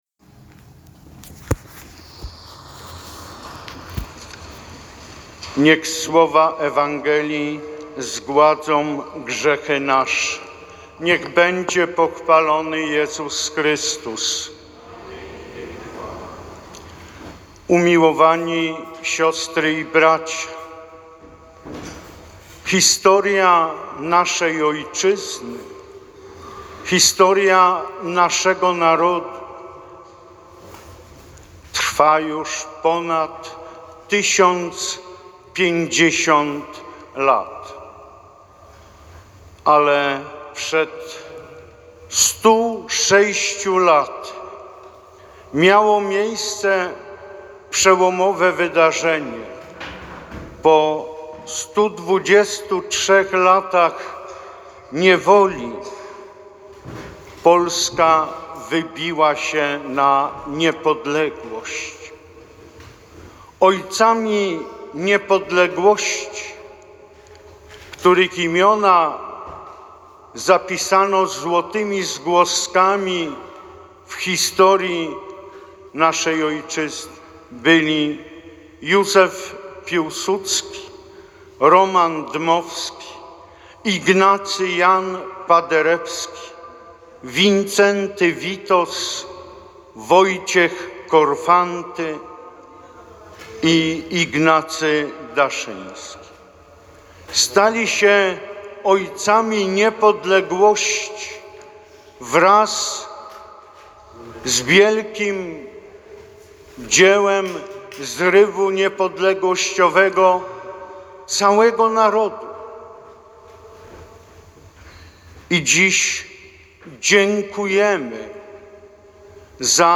W czasie swej homilii celebrans podkreślił, jak ważne było odzyskanie niepodległości przez Polskę po 123 latach niewoli.
homilia-ks.-proboszcza.mp3